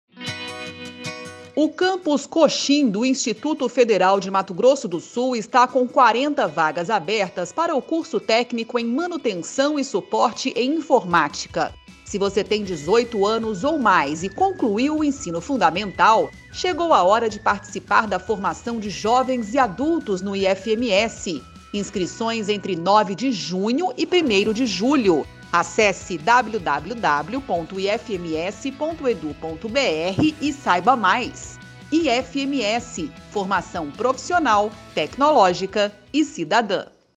Spot - Curso técnico Proeja em Manutenção e Suporte em Informática em Coxim
Áudio enviado às rádios para divulgação institucional do IFMS